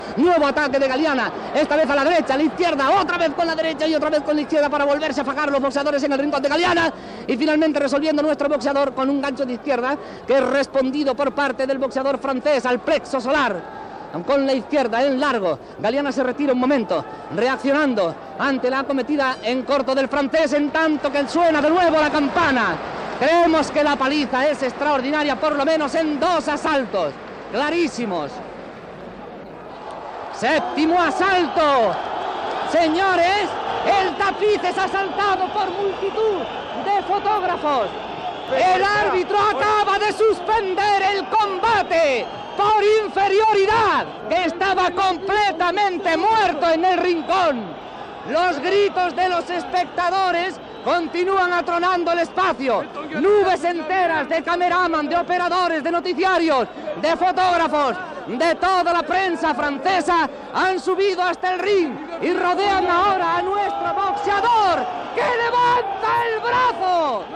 Transmissió des del Palau d'Esports de París del combat de boxa entre Fred Galiana i Ray Famechón que guanya el primer, per inferioritat, i es proclama campió d'Europa del pes pluma.
Esportiu